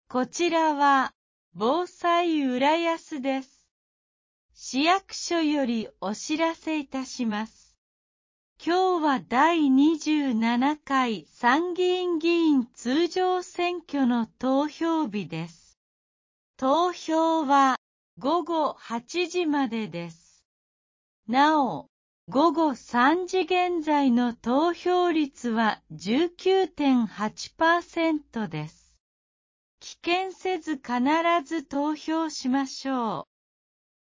第27回参議院議員通常選挙 | 浦安市防災行政無線 放送内容掲載ホームページ